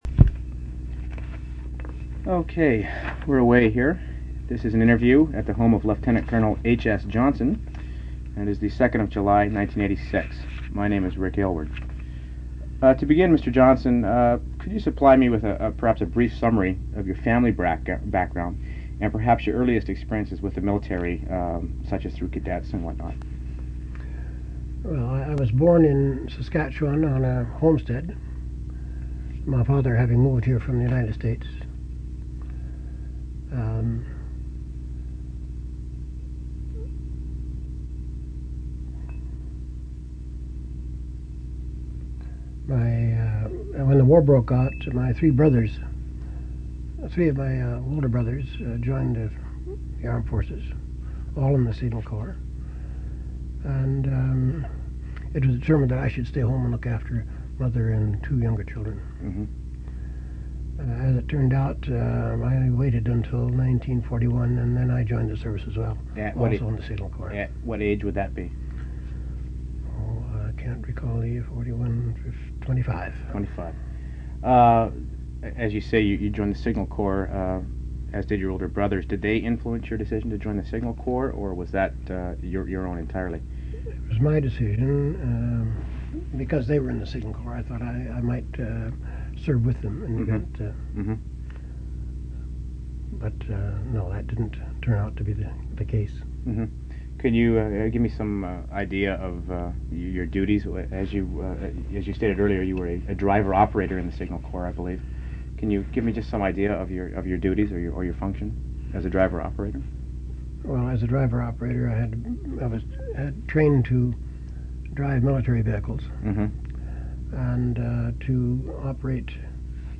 Interview took place on July 2 and 7, 1986.